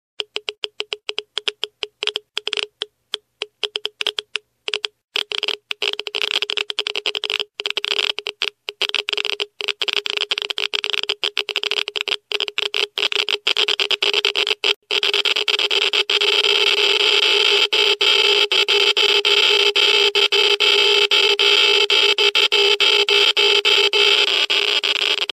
Звуки излучения
На этой странице собраны звуки, связанные с излучением: от фонового космического шума до специфических электромагнитных колебаний.
Прослушайте звуки счётчика Гейгера при радиации и скачайте mp3